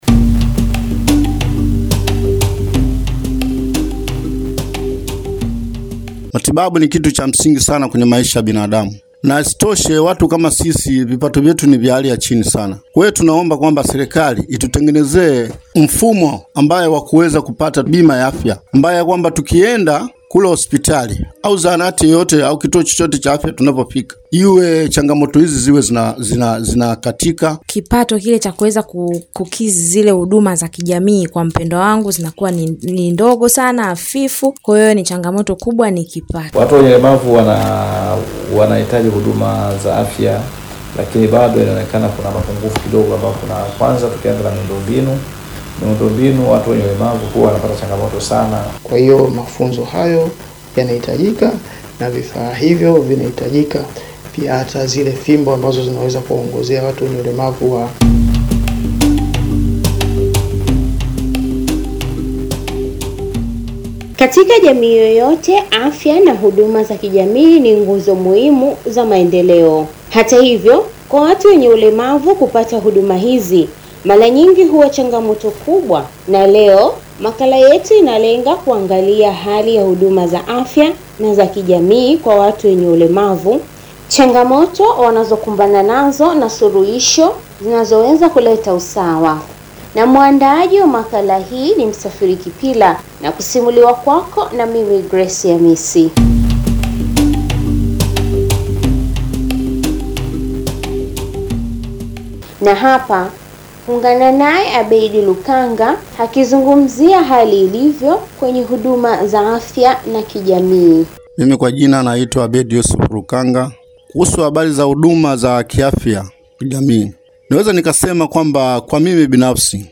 makala-upatikanaji-wa-huduma-za-kijamii-na-afya-kwa-walemavu.mp3